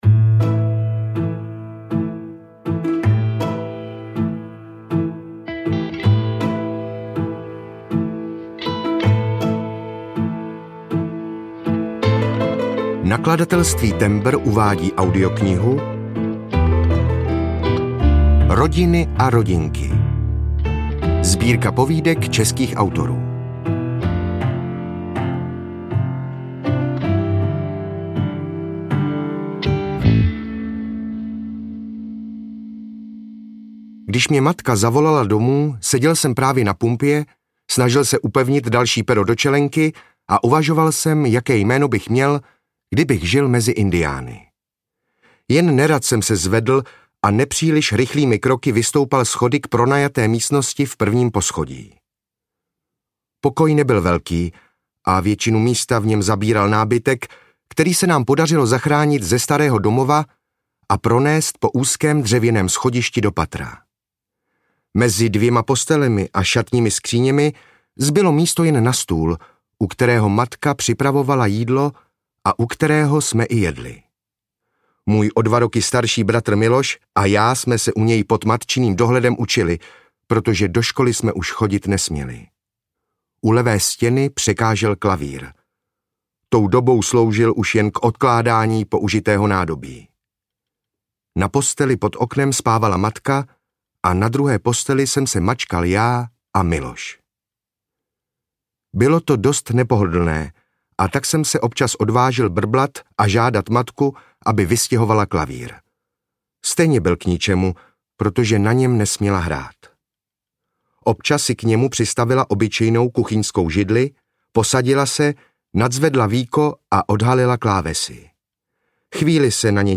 Rodiny a rodinky audiokniha
Ukázka z knihy